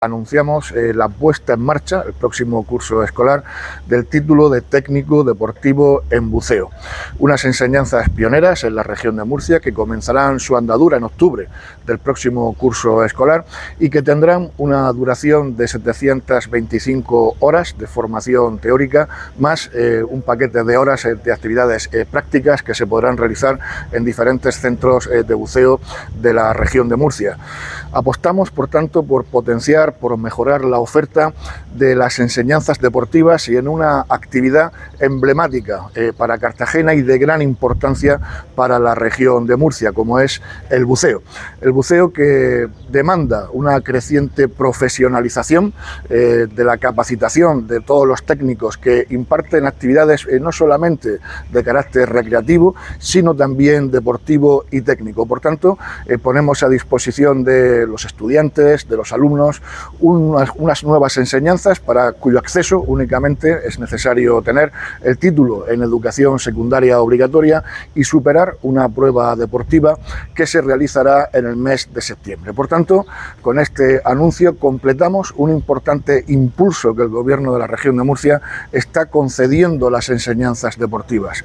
Declaraciones del consejero de Educación y FP, Víctor Marín, sobre la puesta en marcha del nuevo título de FP de Buceo Deportivo